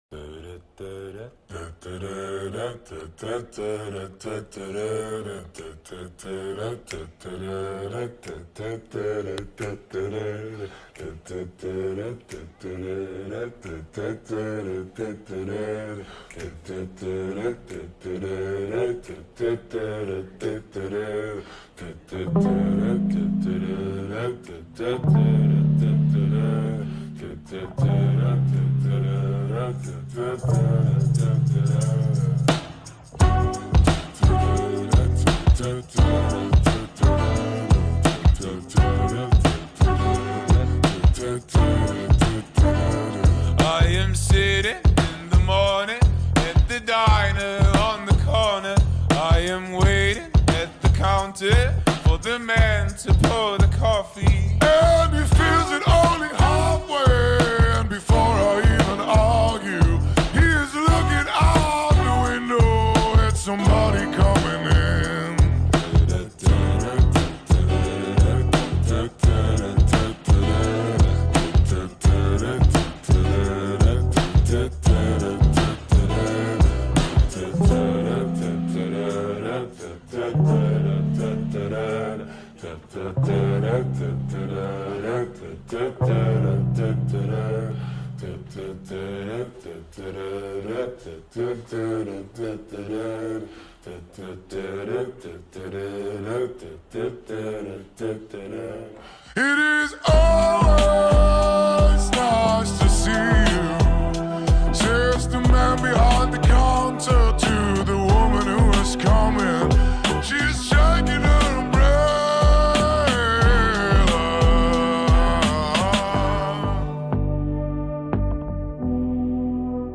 نسخه آهسته کاهش سرعت